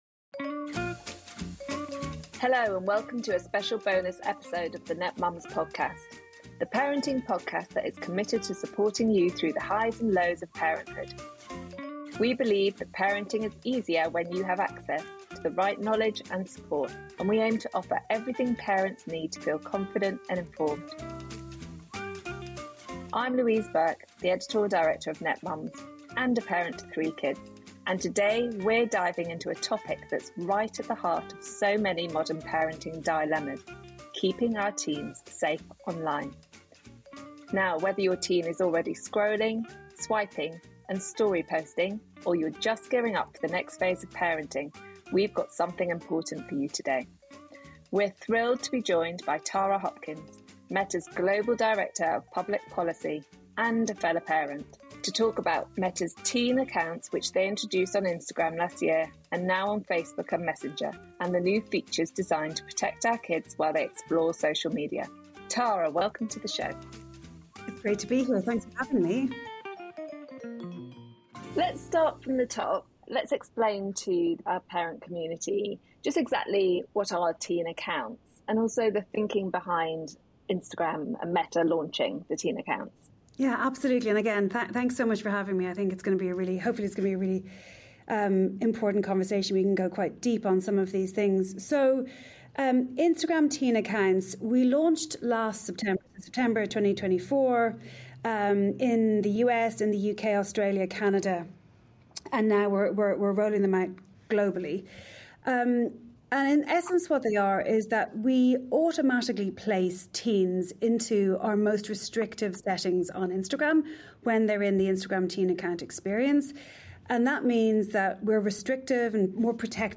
Join us for this informative discussion that aims to empower you with the knowledge and tools needed to support your teens in the digital age.